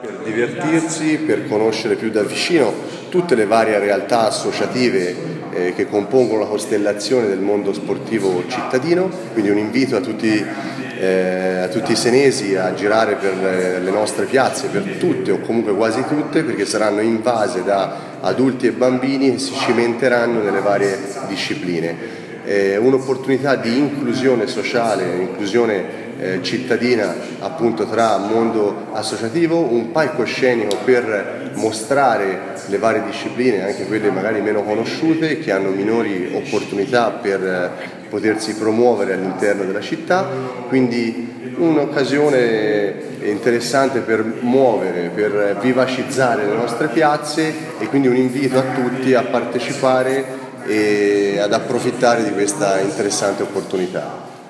Ascolta l’intervista all’assessore allo sport Leonardo Tafani